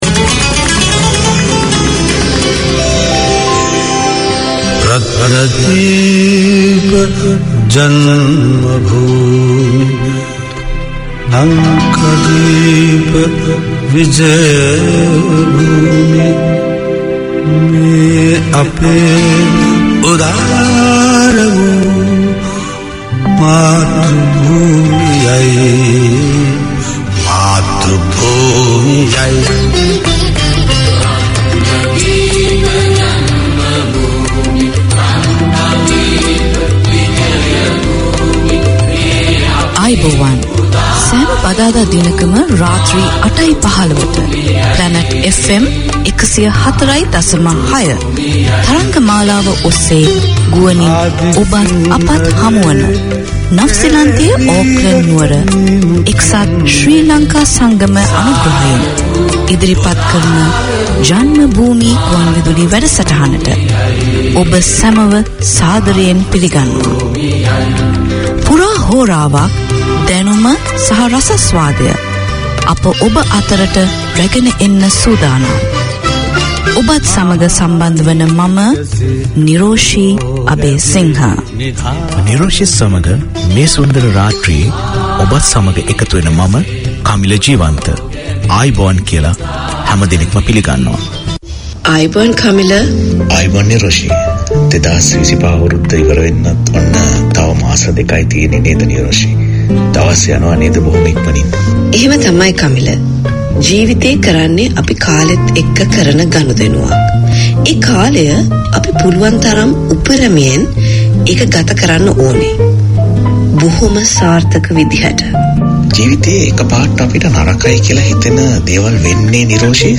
For 65 minutes weekly Janma Bhoomi aims to keep Singhalese in touch with their motherland and educate the younger generation born here about the history and nature of Sri Lanka. There are historical stories and events, news and current affairs, poetry, prose and drama, festival celebrations, all wrapped around with both old and modern music.
Community magazine